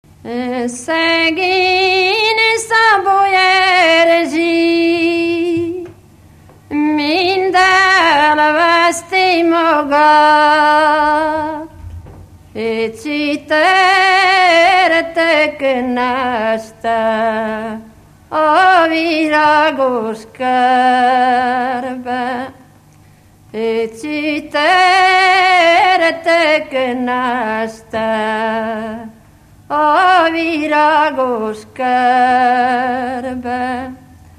Moldva és Bukovina - Moldva - Lészped
ének
Műfaj: Ballada
Stílus: 3. Pszalmodizáló stílusú dallamok